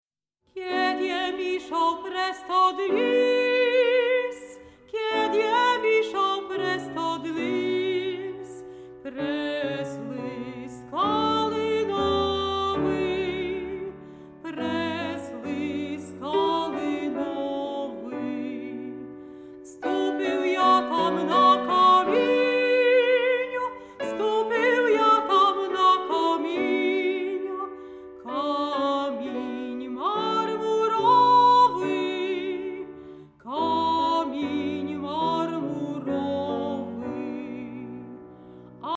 mezzosopran
fortepian